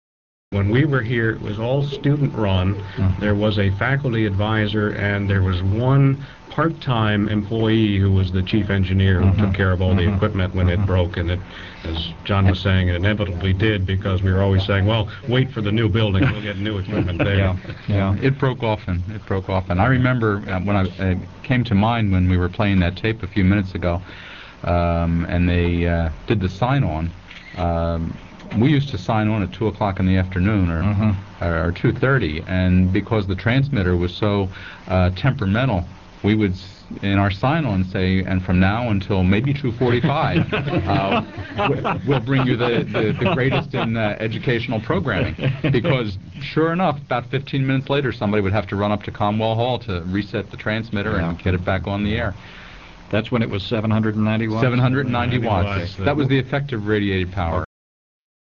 Here are excerpts from that broadcast: